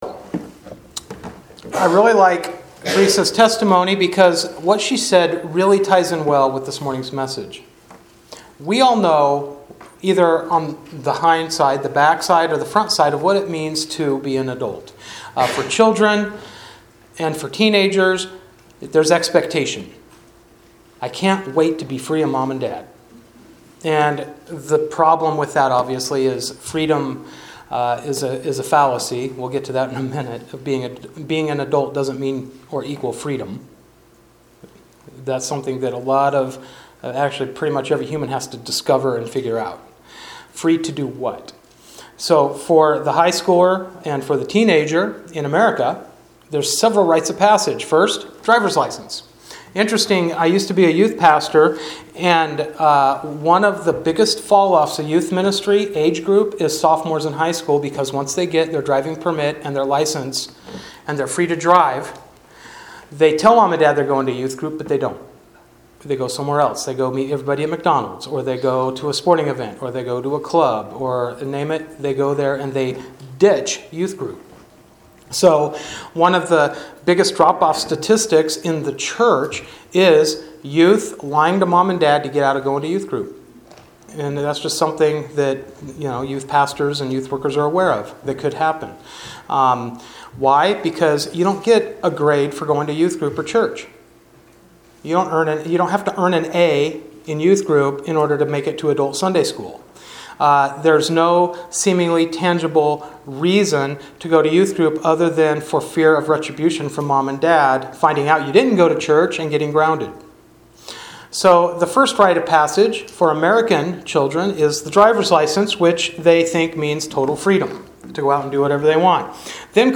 Passage: Galatians 4:1-7 Service Type: Sunday Morning Worship